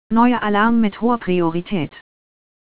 AlarmMajor.wav